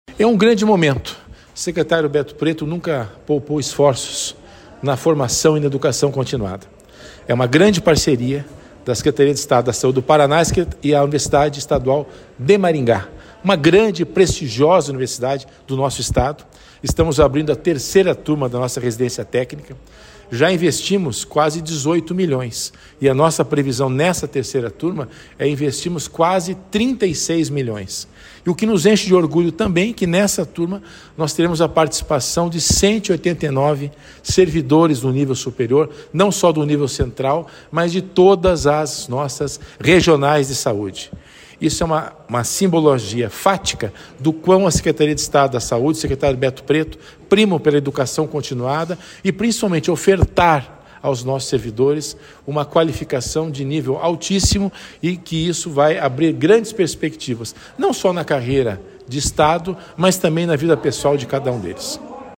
Sonora do diretor-geral da Sesa, César Neves, sobre a qualificação de profissionais da saúde para a gestão do SUS